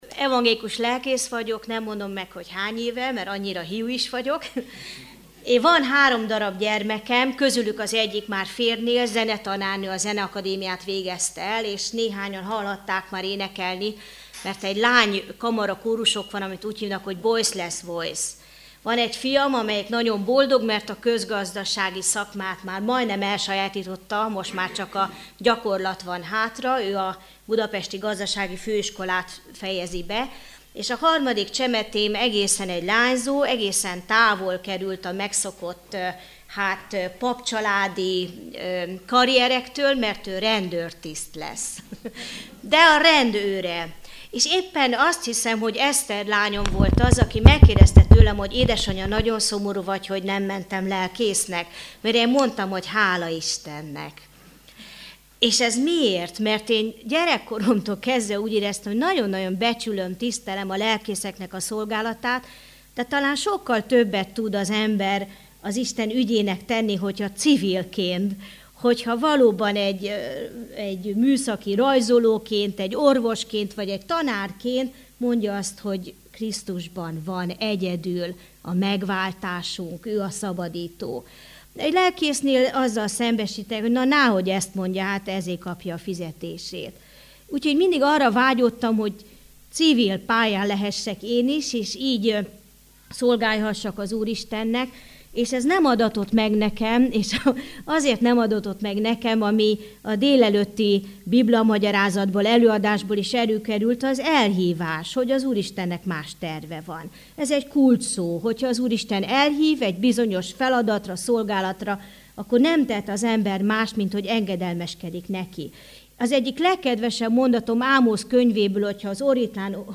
Missziói konferencia 2013 - Evangélikus Missziói Központ
Előadó Téma Meghallgatás Letöltés Szemerei János püspök Előadás